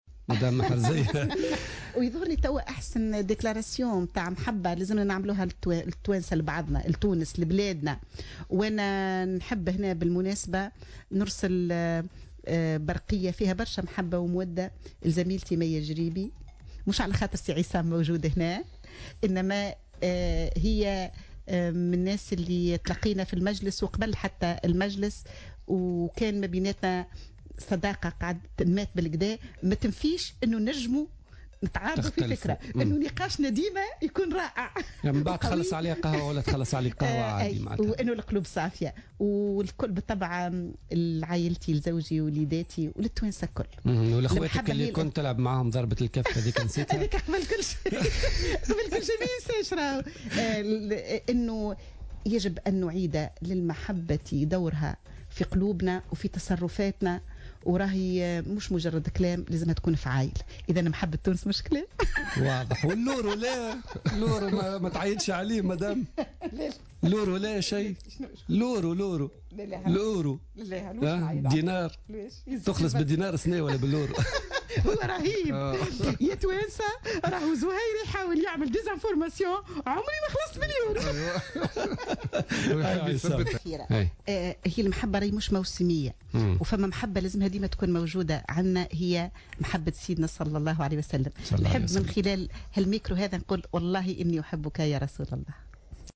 Invitée de l’émission Politica du vendredi 13 février 2015 sur les ondes de Jawhara FM, la dirigeante au sein d’Ennahdha, Meherzia Laâbidi, a déclaré que les Tunisiens doivent s'aimer les uns les et que cet amour doit se traduire par des actes et par la manière de se comporter entre eux.